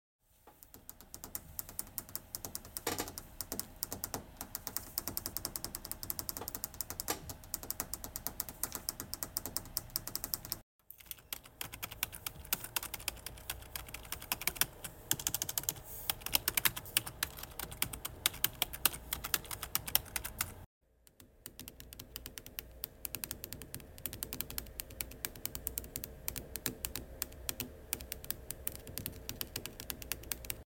Tap Tap Tap !!